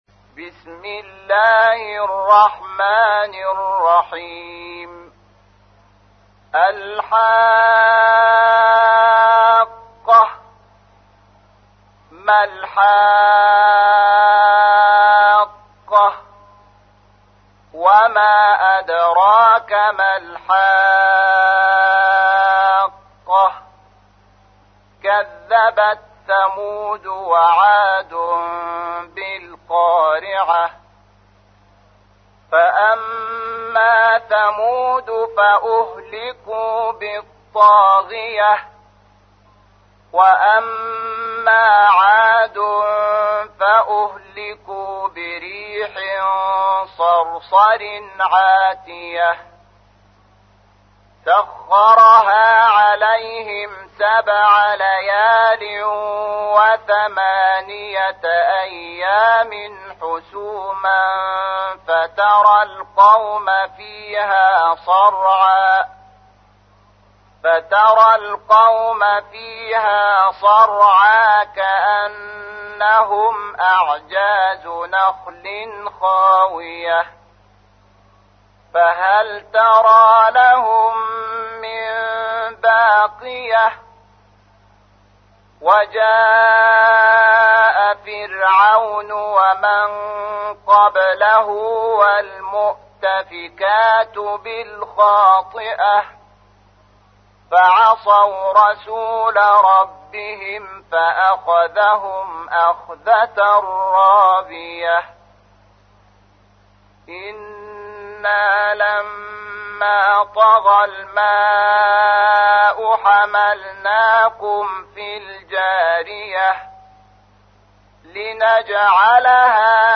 تحميل : 69. سورة الحاقة / القارئ شحات محمد انور / القرآن الكريم / موقع يا حسين